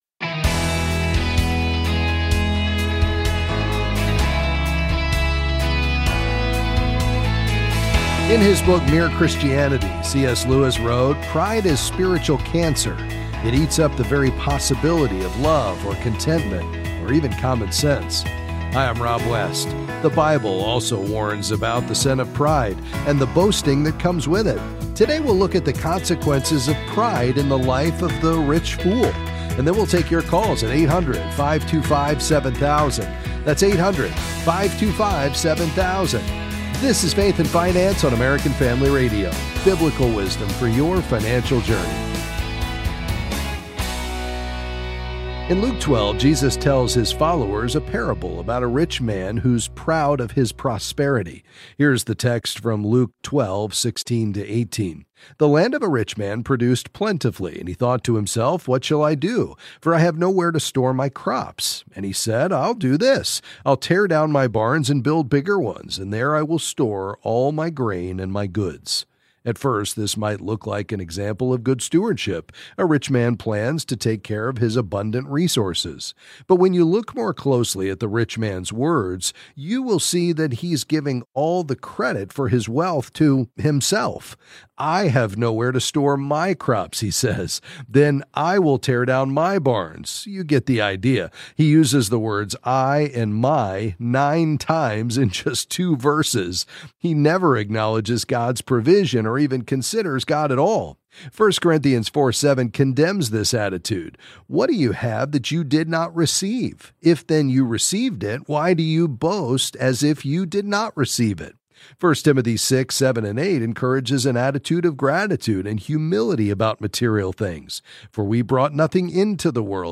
Then he’ll take your calls and various financial questions.